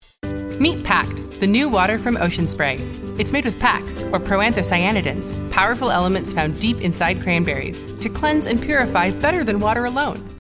Pact from Ocean Spray Radio Ad Demo
My voice is warm and comforting, relatable, humorous, and authoritative. As a natural alto I am very comfortable in low, smokey and intimate ranges.